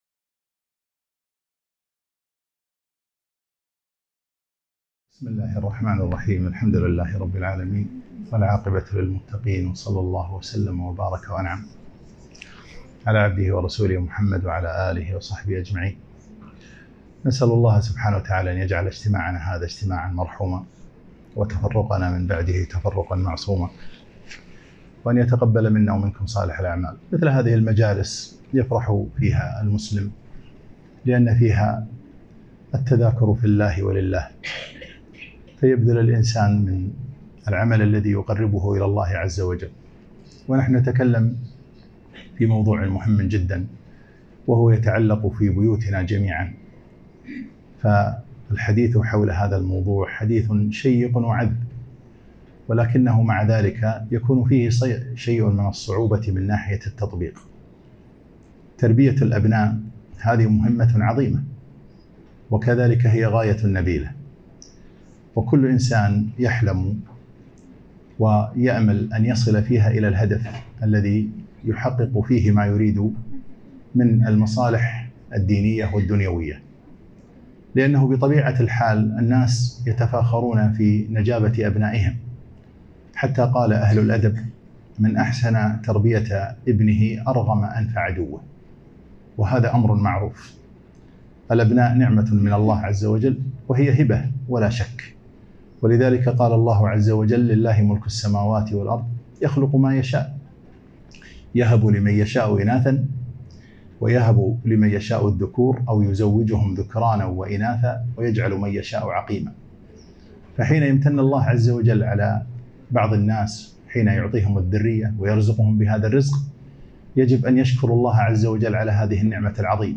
محاضرة رائعة - أبناؤنا ومراحل العمر